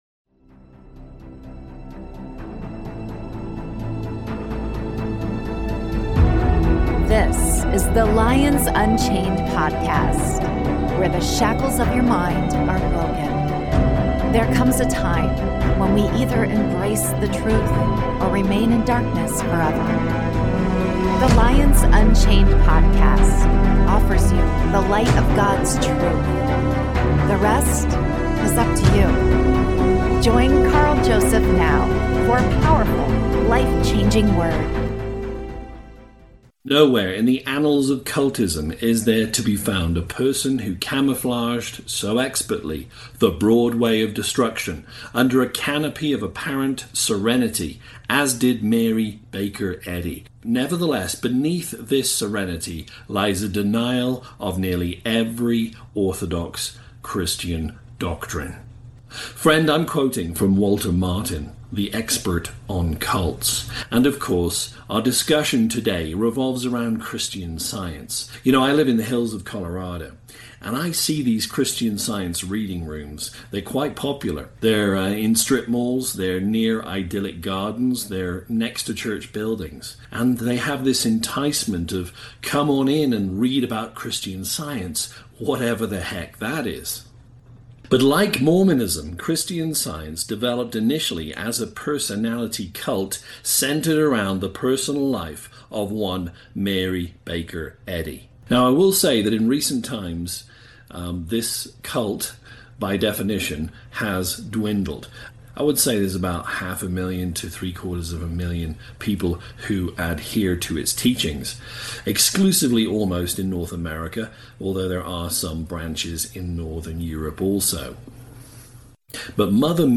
Is ‘Christian Science’ merely another Christian denomination or is there a sinister undertone to the ‘Reading Rooms,’ scattered throughout the nation? 1st Timothy 6:20, warns of ‘science falsely so called,’ which refutes saving grace through faith, offering salvation via the tree of knowledge instead (Gen 2:17). In this insightful broadcast